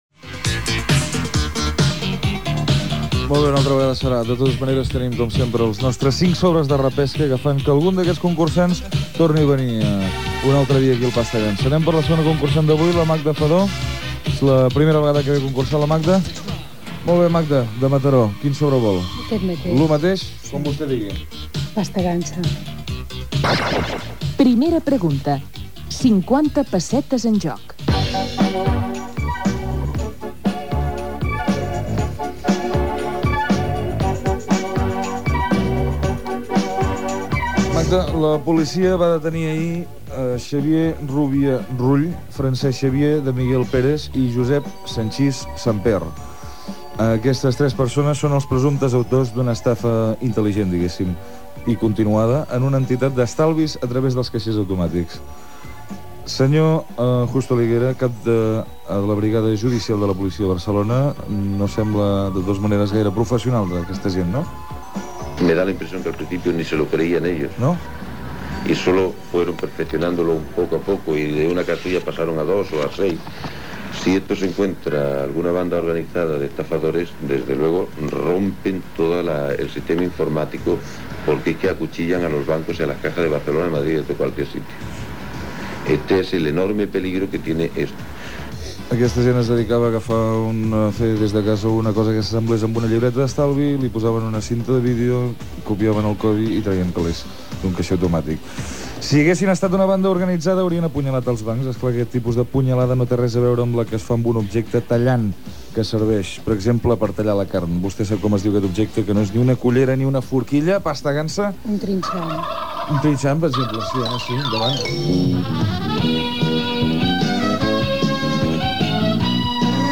Preguntes i respostes de la concursant.